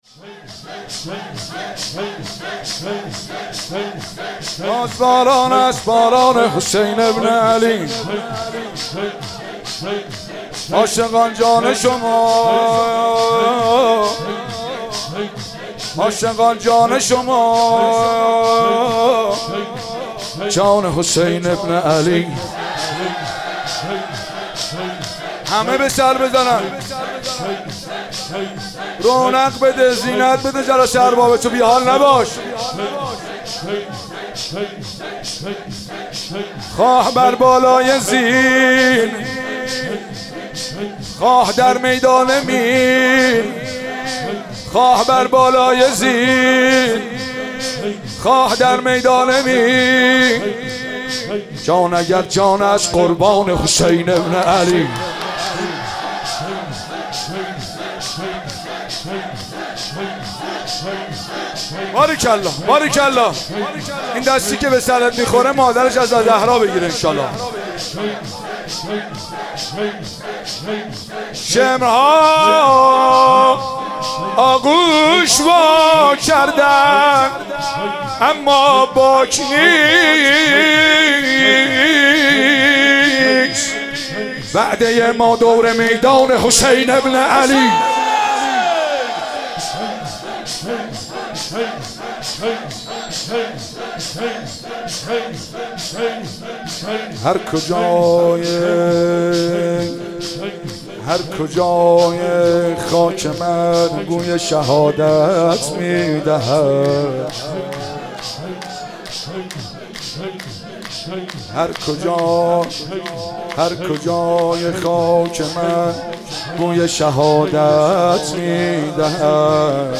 شب دوم محرم الحرام 95/ مسجد مقدس جمکران
شور و زمزمه